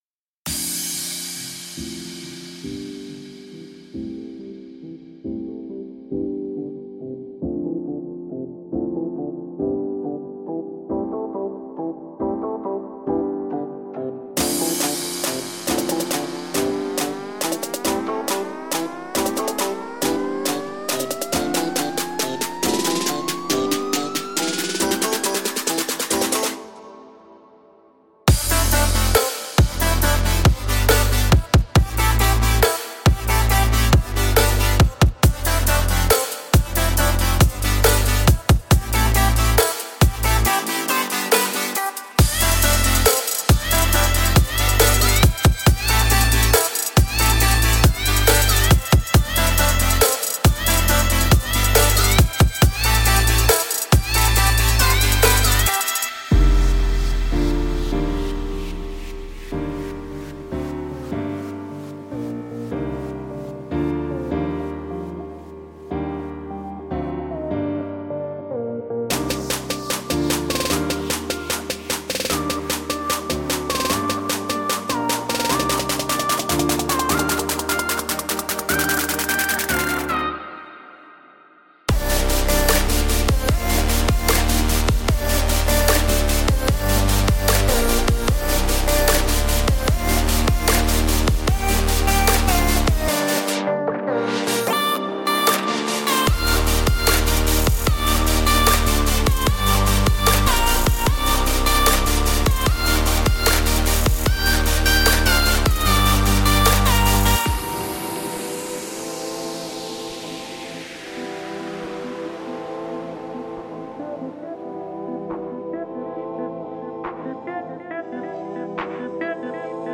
您会得到喊，短语，声码器音色，引线，键，打击垫，断音和Fx。